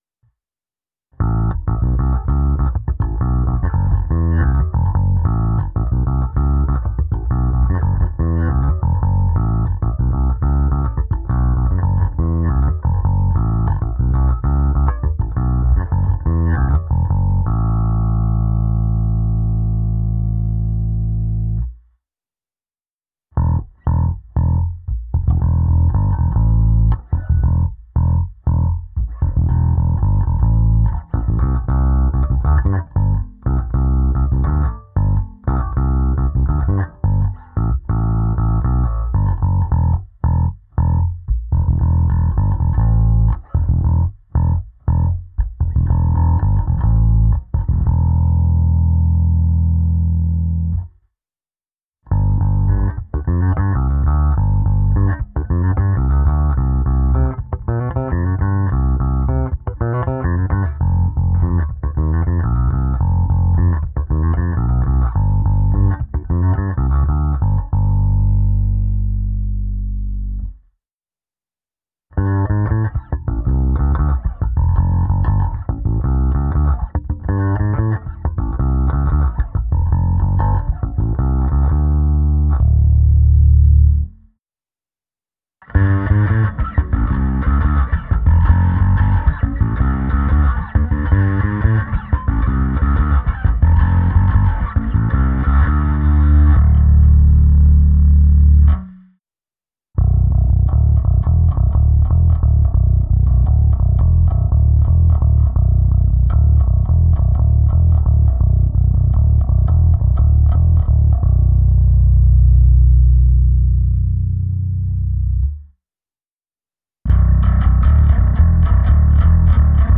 Zvuk má očekávatelně moderní charakter, je pevný, konkrétní, vrčí, má ty správné středy důležité pro prosazení se v kapele.
Nahrávka se simulací aparátu na oba snímače, ukázka zkreslení, ukázka na struně H, hra slapem, a nakonec hra akordů ve vyšších polohách s využitím struny C